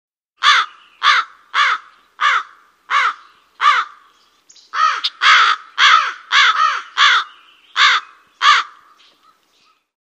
На этой странице собраны разнообразные звуки ворон и воронов: от одиночных карканий до хоровых перекличек.
Карканье вороны третий вариант